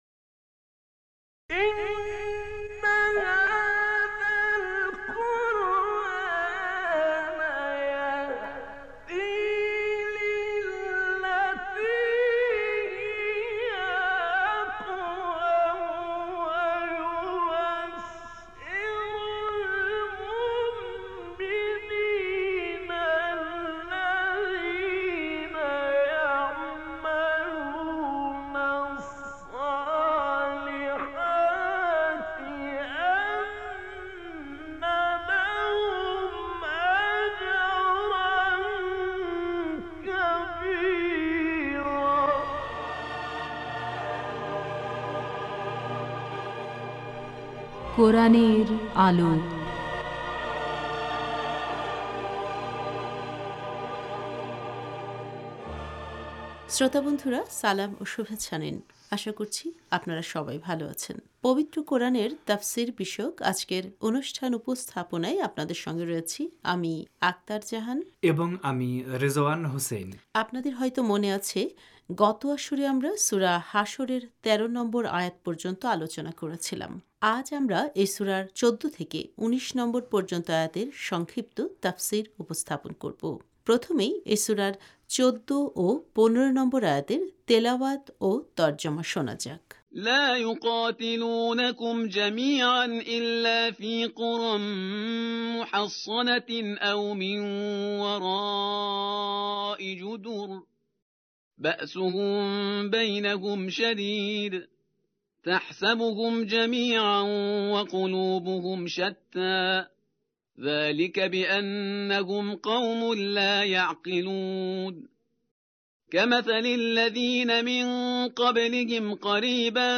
আজ আমরা এই সূরার ১৪ থেকে ১৯ নম্বর পর্যন্ত আয়াতের সংক্ষিপ্ত তাফসির উপস্থাপন করব। প্রথমেই এই সূরার ১৪ ও ১৫ নম্বর আয়াতের তেলাওয়াত ও তর্জমা শোনা যাক: